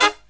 Index of /90_sSampleCDs/USB Soundscan vol.29 - Killer Brass Riffs [AKAI] 1CD/Partition F/03-HIGHHITS2
HIGH HIT26-R.wav